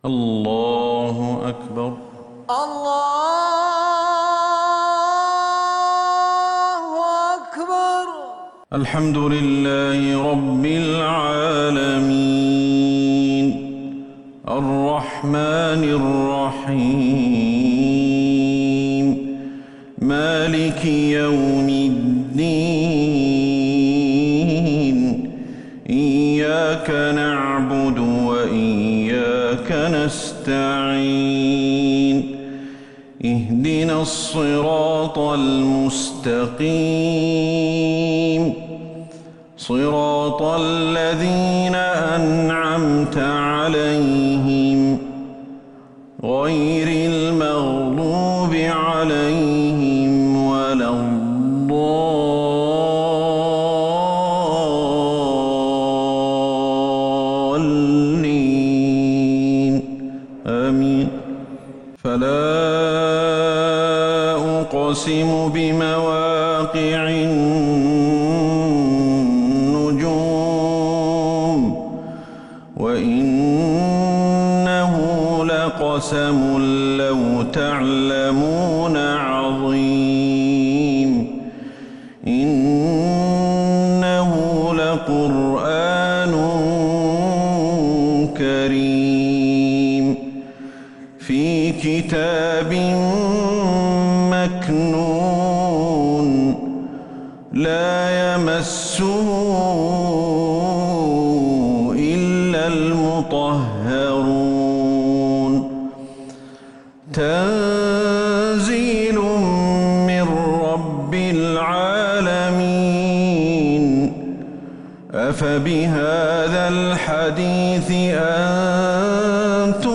صلاة المغرب
تِلَاوَات الْحَرَمَيْن .